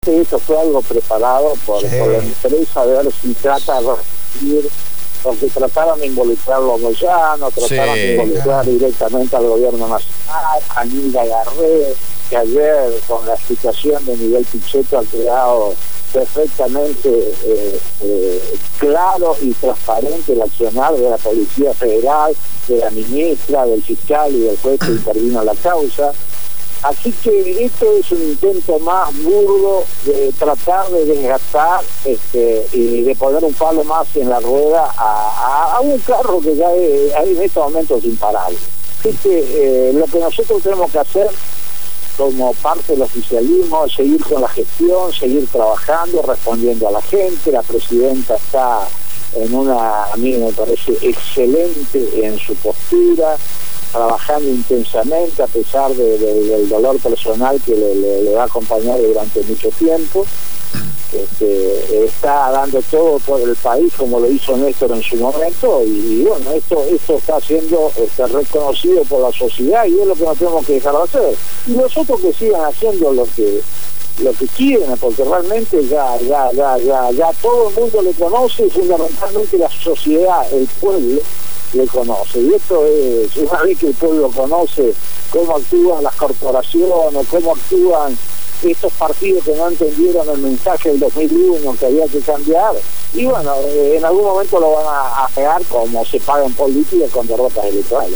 «La oposición se comporta como esos jugadores que no llegan a tiempo y en cualquier momento la sociedad les va a sacar la tarjeta roja» fueron las palabras del Senador Nacional por la provincia de Misiones Eduardo Enrique Torres en una entrevista realizada por el programa «Cambio y futuro en el aire» (Jueves de 20 a 22hs.) por Radio Gráfica.